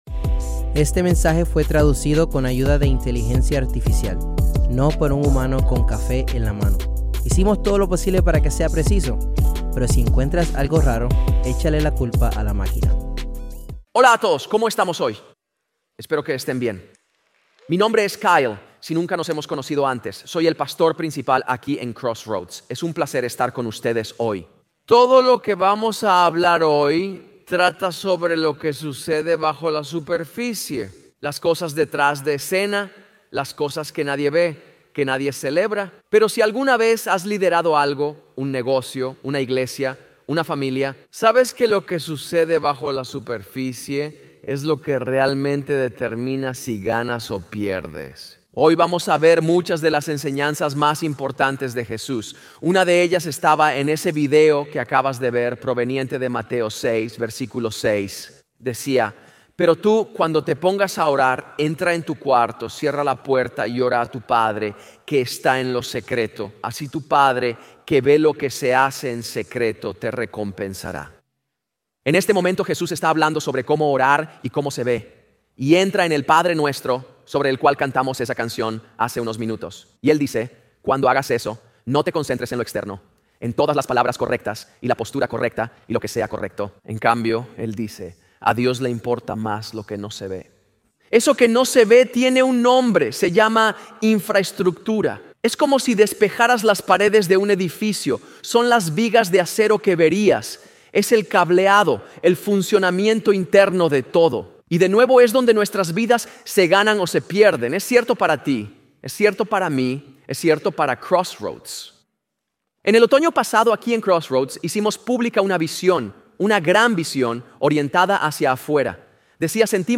Grabado en vivo en la Iglesia Crossroads en Cincinnati, Ohio.